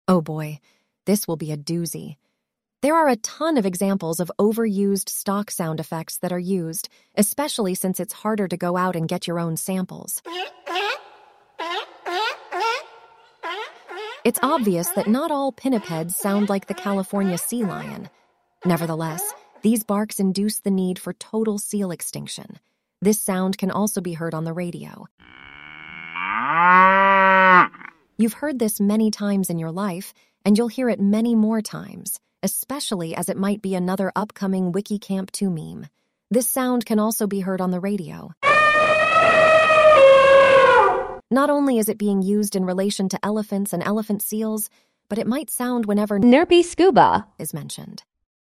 File:ElevenLabs reads Trope excerpt.mp3
contribs)elephant is quieter